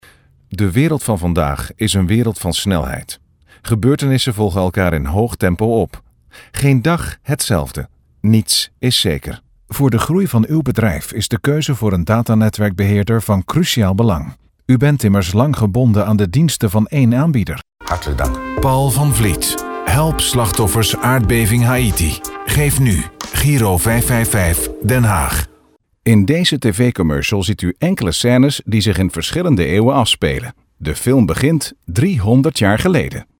Stimme mit eigener studio und ISDN-APT.
Sprechprobe: Industrie (Muttersprache):
Excellent voice-over for trailers/commercials/films & impersonations in Dutch and English.